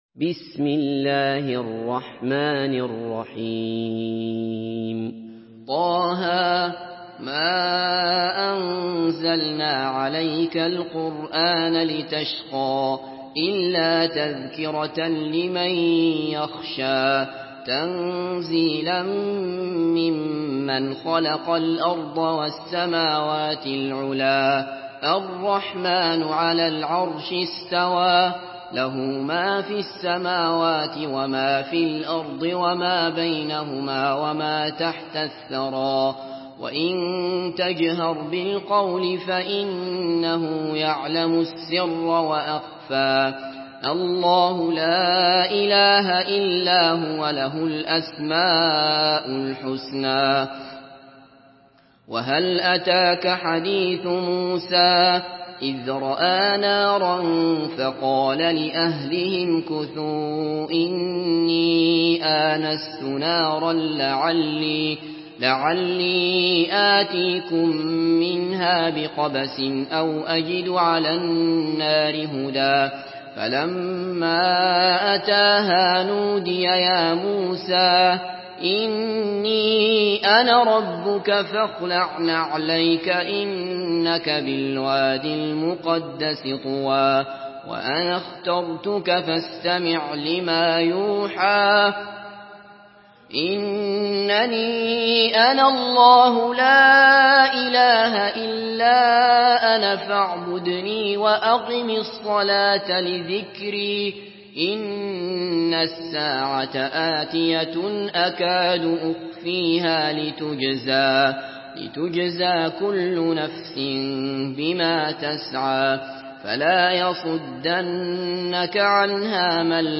Surah طه MP3 by عبد الله بصفر in حفص عن عاصم narration.
مرتل